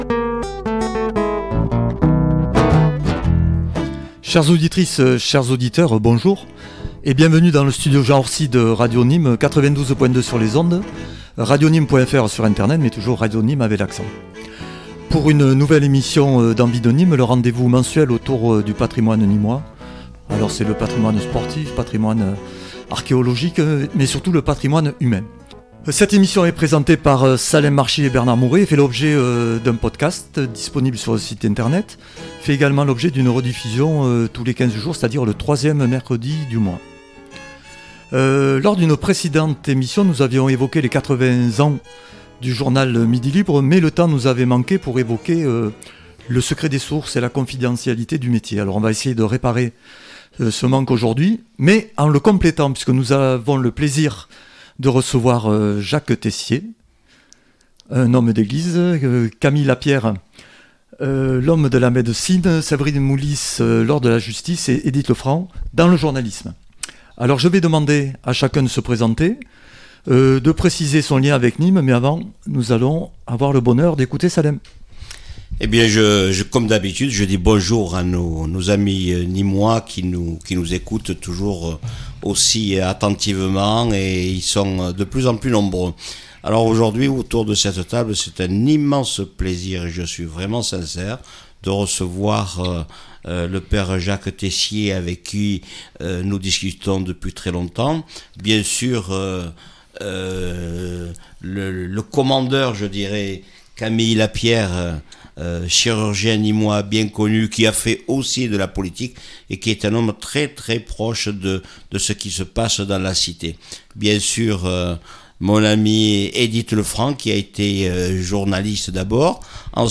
en direct des studios "Jean Orsi"